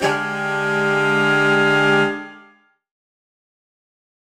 UC_HornSwellAlt_Dminb5.wav